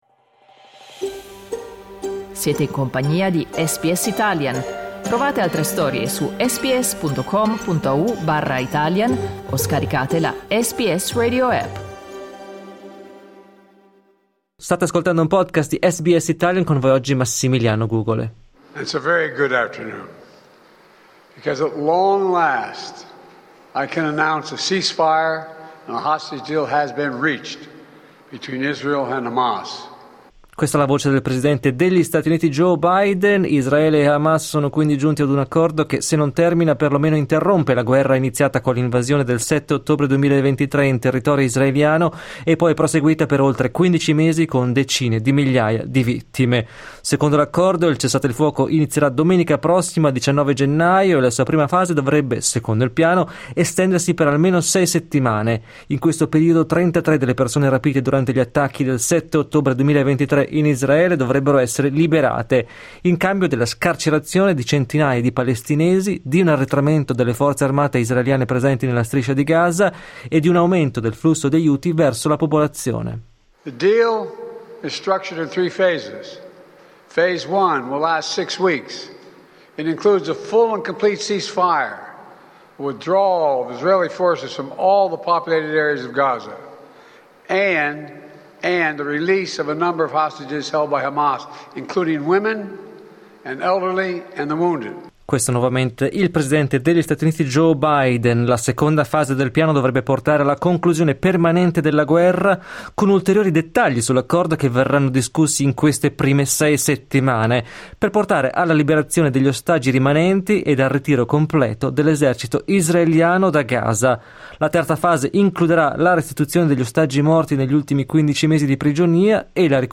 Ascolta l'analisi del giornalista